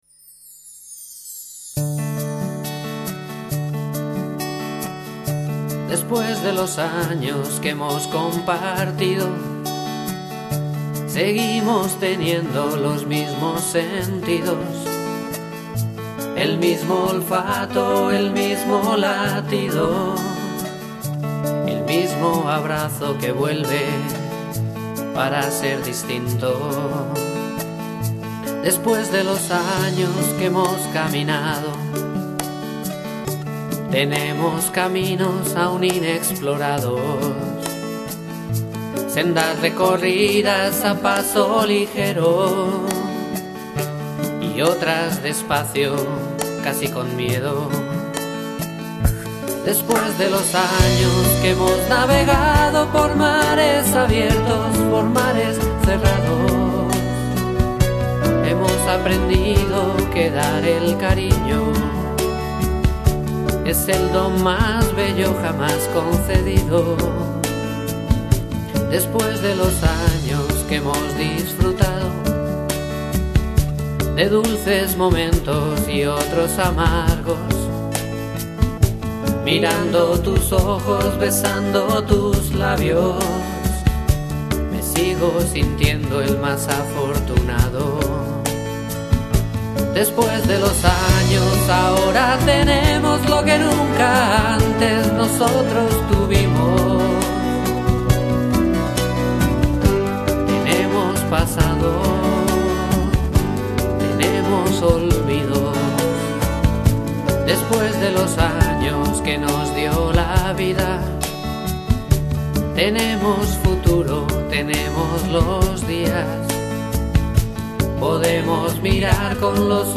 Cantautor madrileño